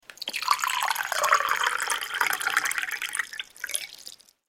دانلود صدای آب 43 از ساعد نیوز با لینک مستقیم و کیفیت بالا
جلوه های صوتی